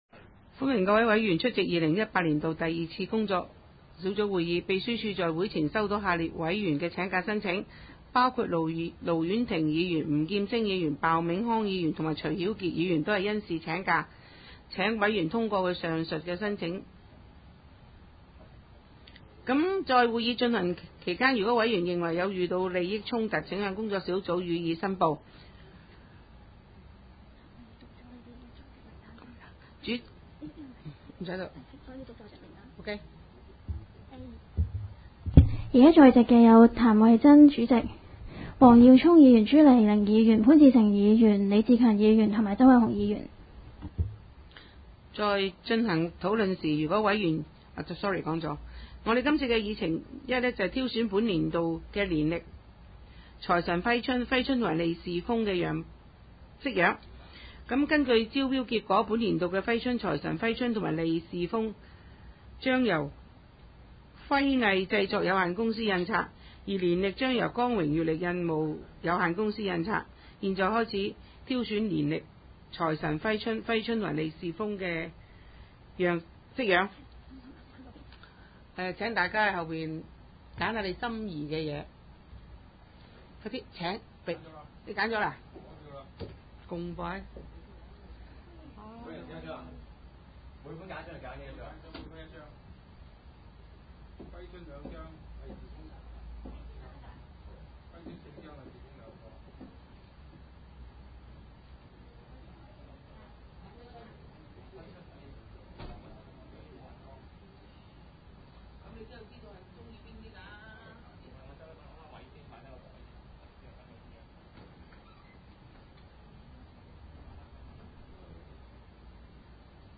工作小组会议的录音记录
地点: 香港葵涌兴芳路166-174号葵兴政府合署10楼 葵青民政事务处会议室